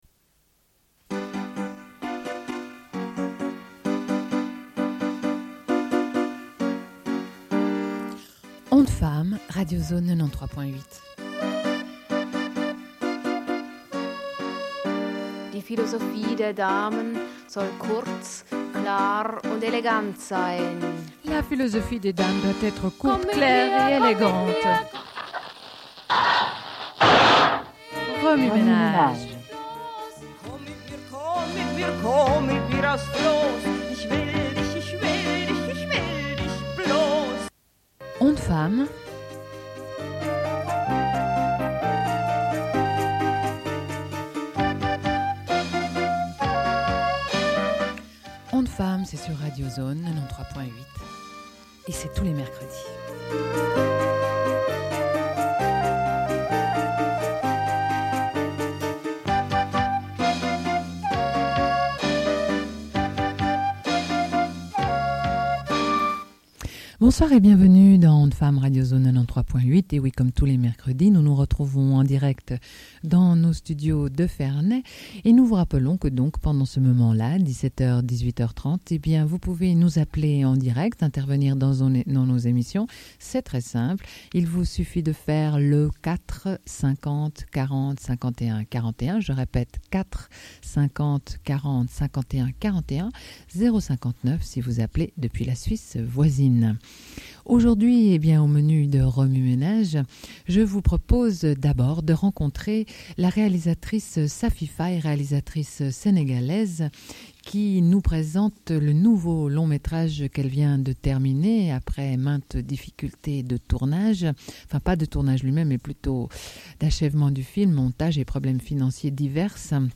Sommaire de l'émission : au sujet du film Mossane avec la réalisatrice sénégalaise Safi Faye. Diffusion d'un entretien.